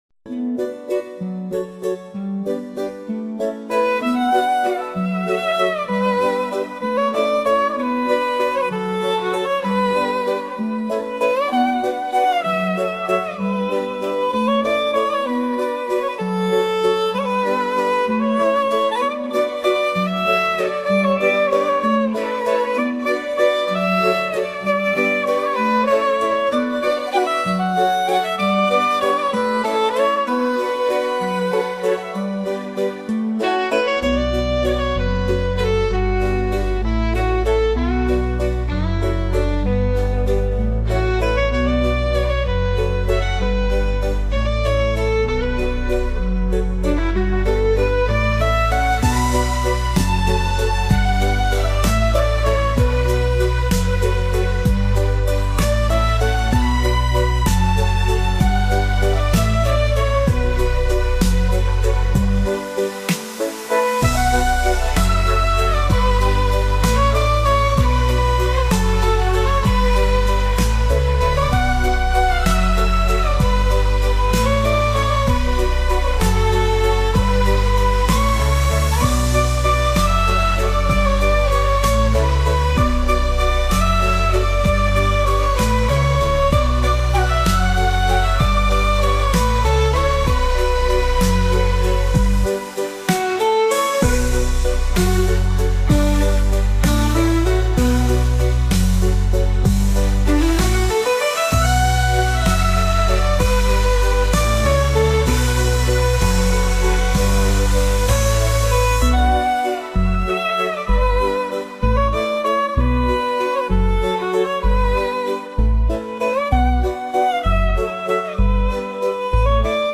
" a serene and spiritual flute piece.